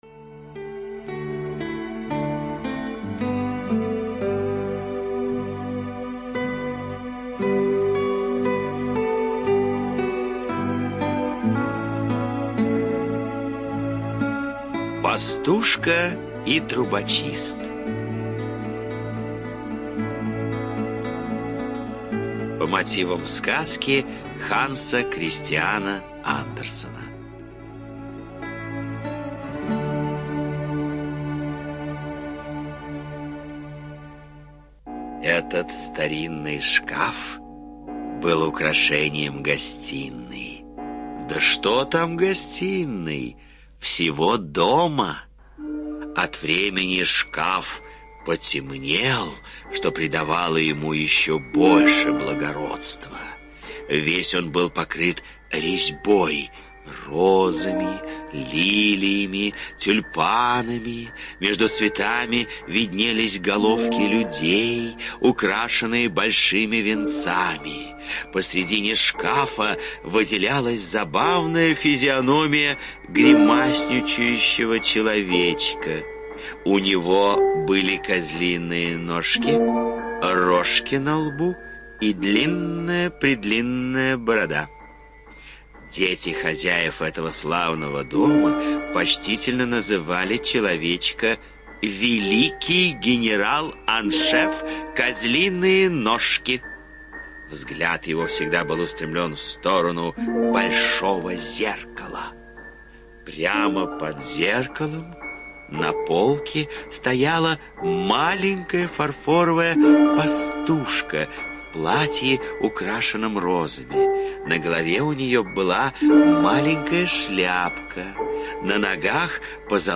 Пастушка и трубочист – Андерсен Г.Х. (аудиоверсия)
Аудиокнига в разделах